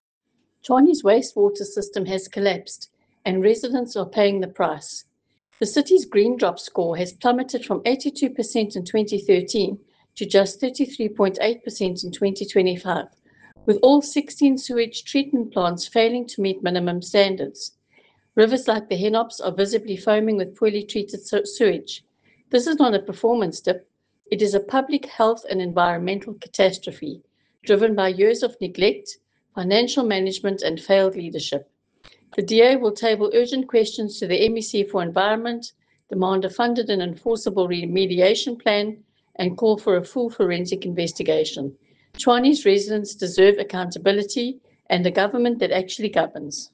Afrikaans soundbites from Leanne De Jager MPL.